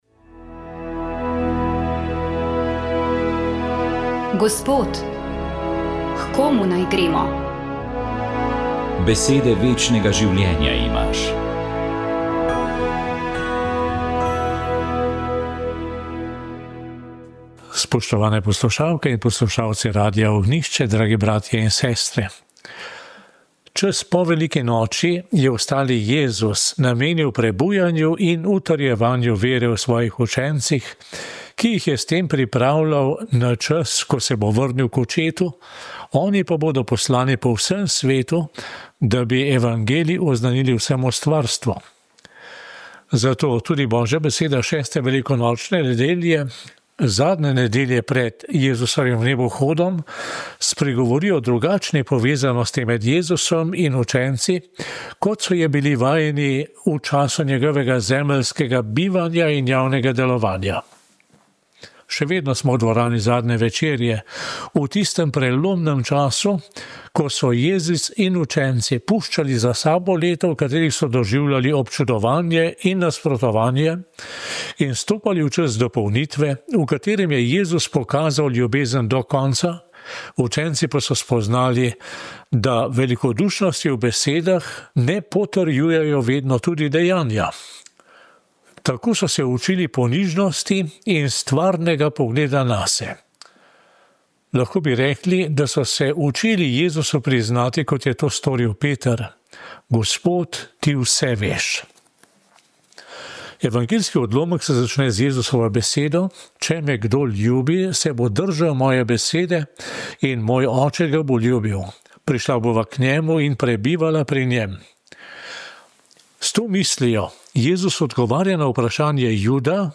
Duhovni nagovor
Reši nas nekega omejenega načina bivanja in nas odpre za neprimerno lepše obzorje.Duhovni nagovor je pripravil novomeški škof Andrej Glavan.